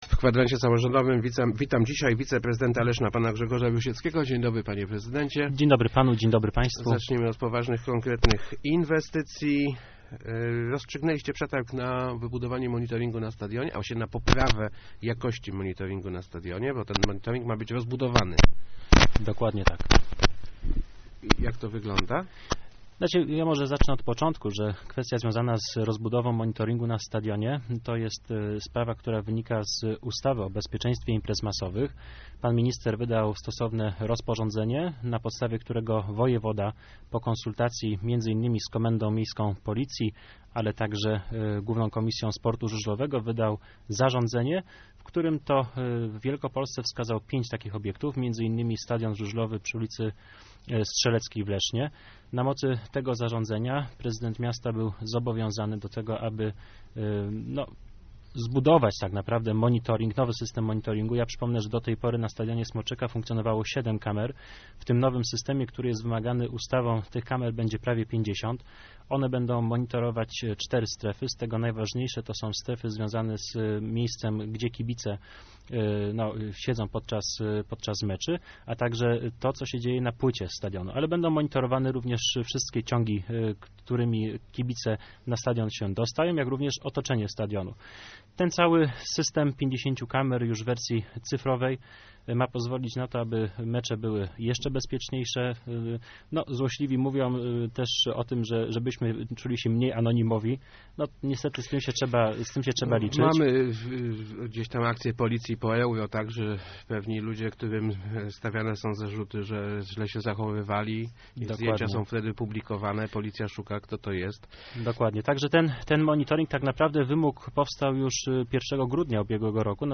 Gościem Kwadransa był wiceprezydent Grzegorz Rusiecki.